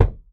Acoustic Kick 15.wav